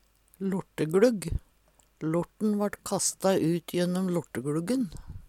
lorteglugg - Numedalsmål (en-US)
See also møkkagLugg (Veggli) Hør på dette ordet Ordklasse: Substantiv hankjønn Kategori: Bygning og innreiing Attende til søk